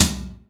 ROOM TOM4A.wav